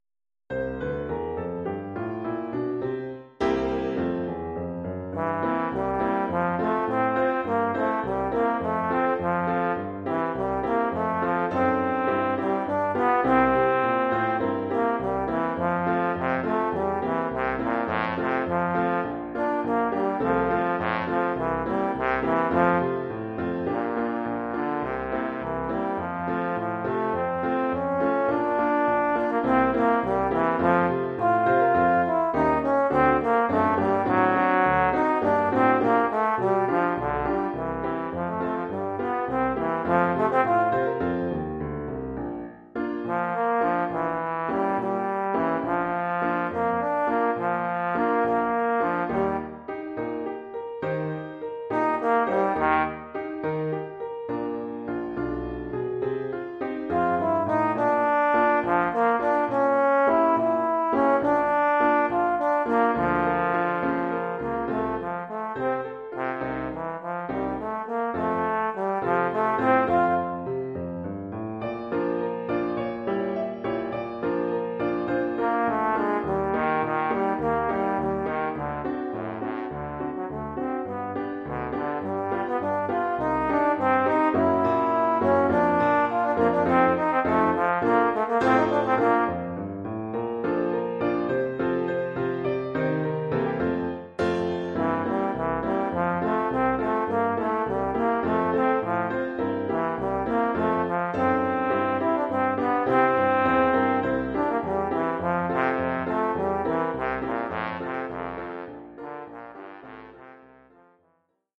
Oeuvre pour trombone et piano.